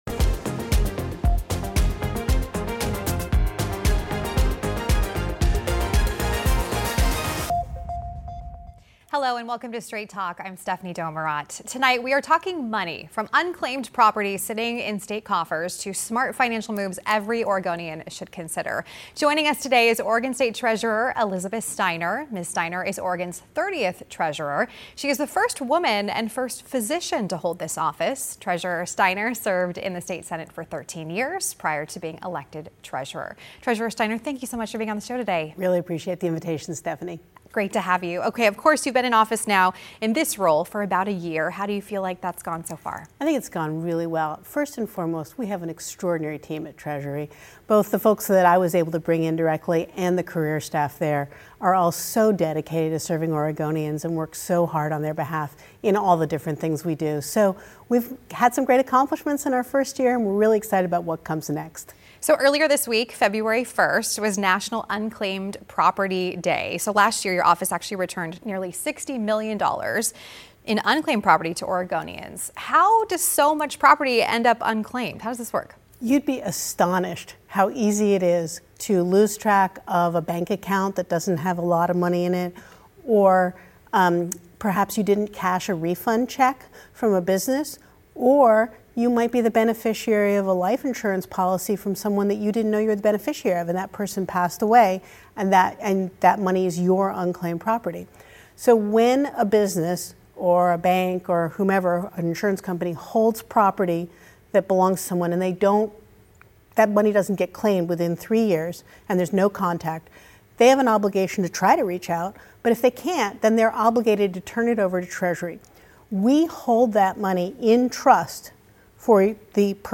Oregon Treasurer Elizabeth Steiner was a guest on this week's episode of Straight Talk to discuss how Oregonians can check for any unclaimed money that they might be owed by the state, as well as her tenure as treasurer.